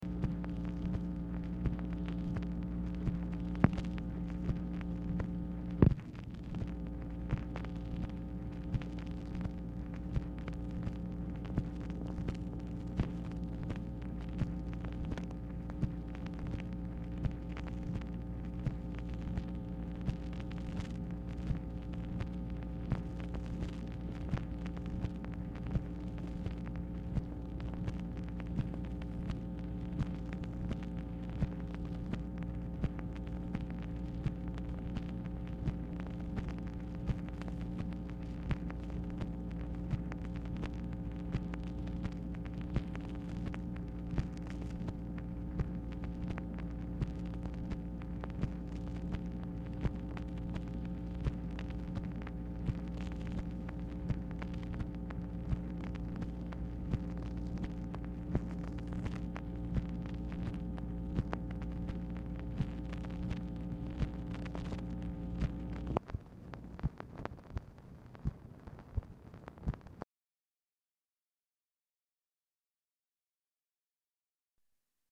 Telephone conversation # 11548, sound recording, MACHINE NOISE, 2/15/1967, time unknown | Discover LBJ
Dictation belt